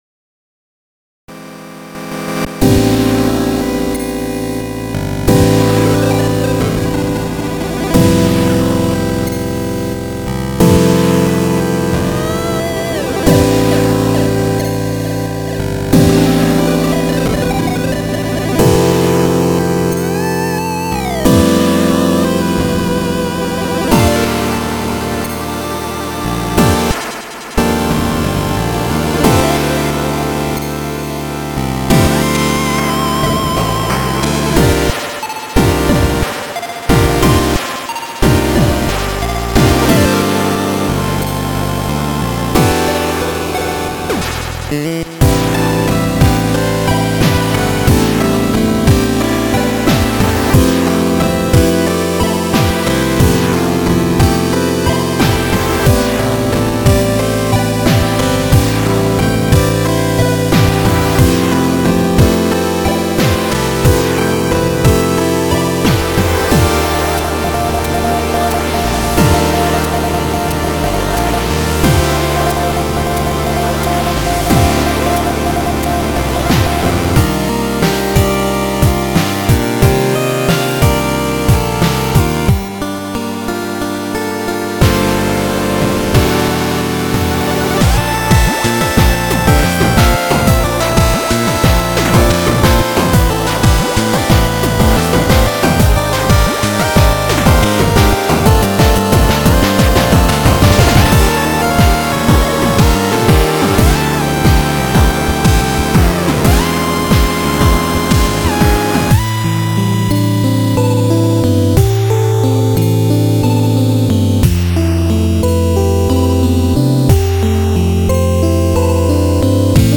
※ (　　)内は、使用音源チップです。
(2A03 + N163)